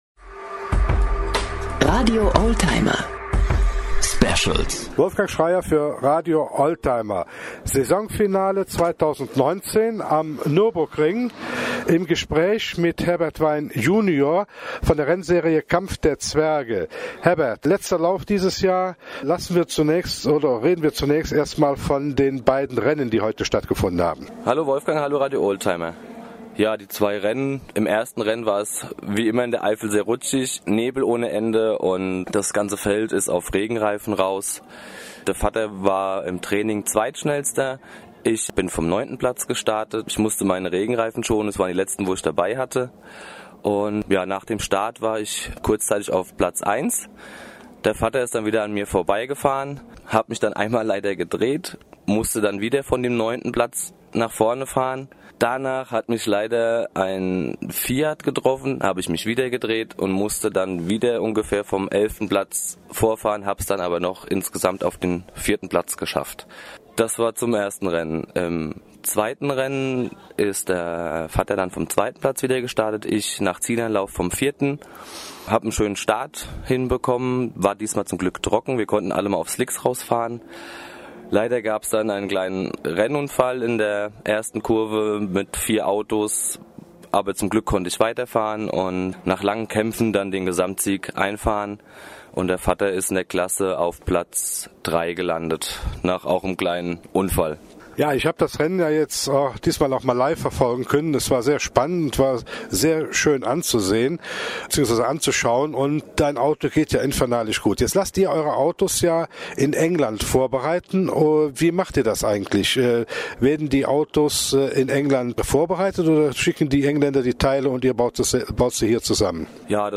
Saisonfinale_am_Nuerburgring.MP3